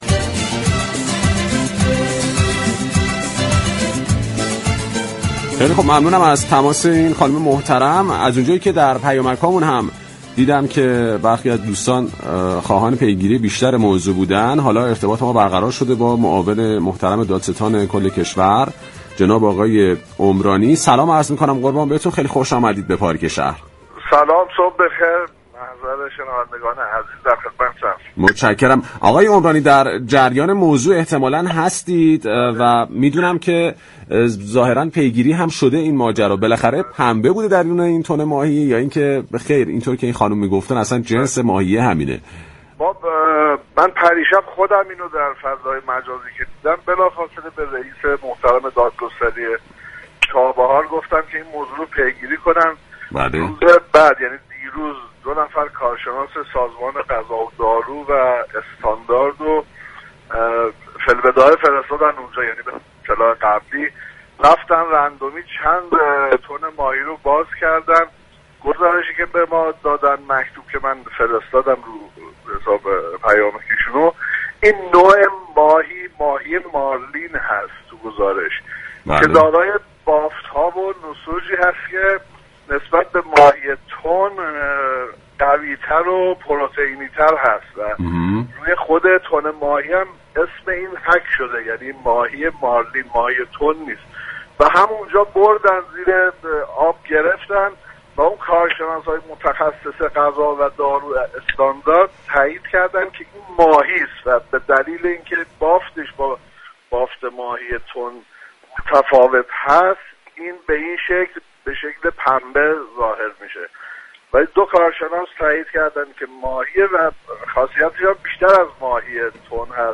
معاون دادستان كل كشور در گفتگو با برنامه پارك شهر رادیو تهران درباره ویدئوی پنبه به جای تن ماهی گفت: محتوای قوطی های كنسرو این كارخانه پنبه نیست بلكه ماهی مارلین است كه بافتی منسجم تر از تن ماهی دارد.